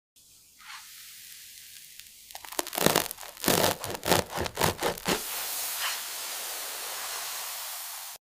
Not your average cheese pull sound effects free download